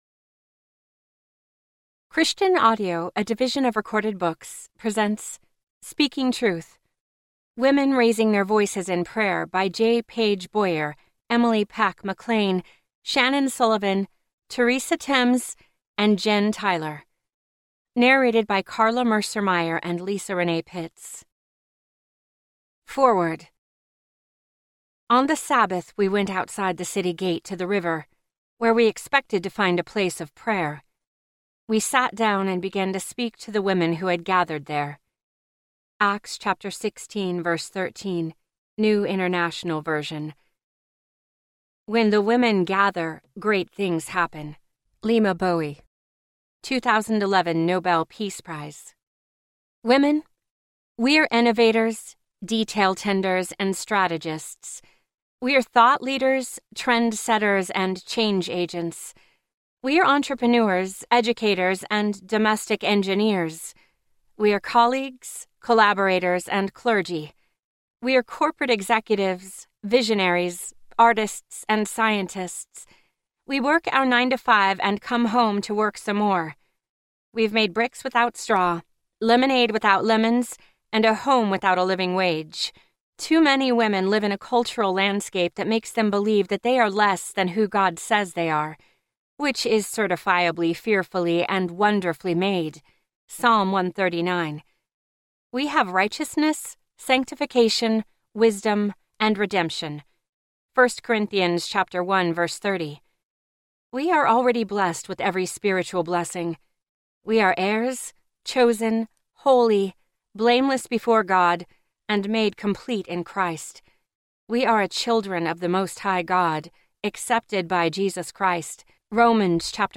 Speaking Truth Audiobook
6.5 Hrs. – Unabridged